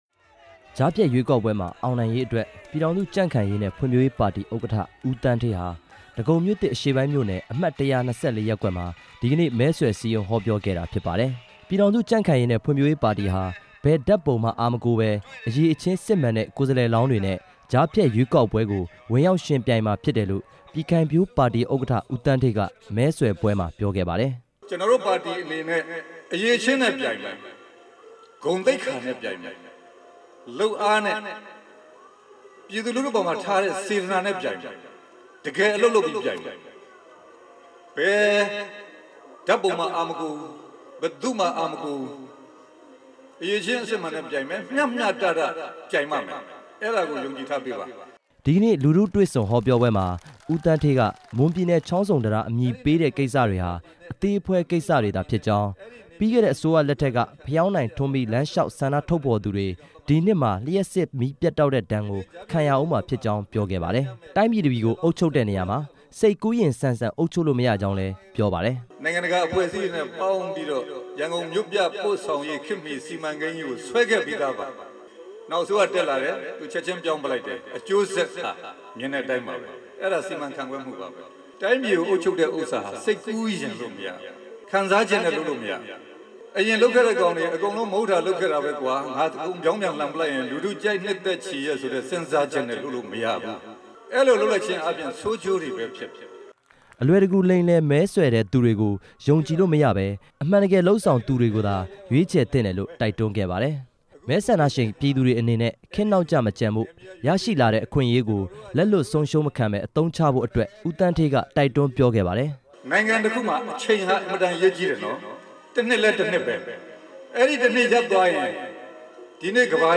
ဧပြီလ ၁ ရက်နေ့မှာ ကျင်းပမယ့် ကြားဖြတ်ရွေးကောက်ပွဲမှာ မဲဆန္ဒရှင်တွေ မဲပေးမမှားဖို့နဲ့ အလိမ်မခံရအောင် ရွေးချယ်ဖို့ လိုအပ်ကြောင်း ပြည်ထောင်စု ကြံ့ခိုင်ရေးနဲ့ ဖွံံ့ဖြိုးရေးပါတီ ဥက္ကဌ ဦးသန်းဌေးက ဒီကနေ့ မဲဆွယ်စည်းရုံးဟောပြောပွဲမှာ ပြောပါတယ်။